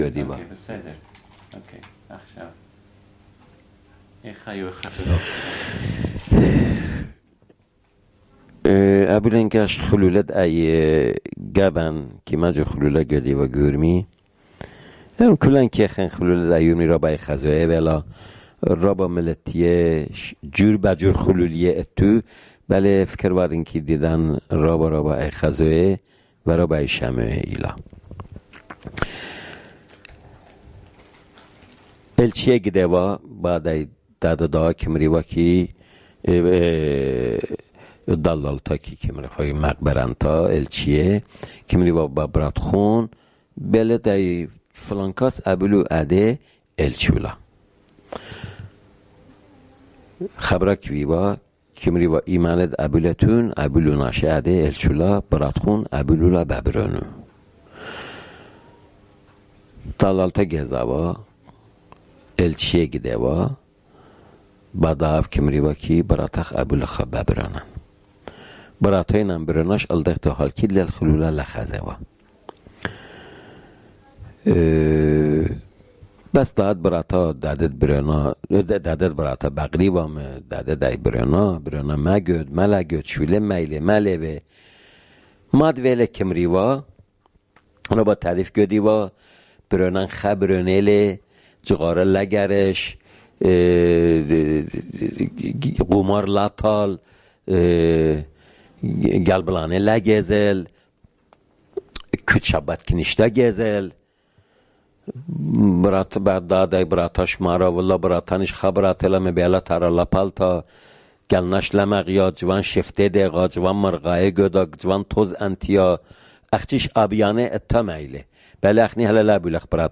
Urmi, Jewish: Weddings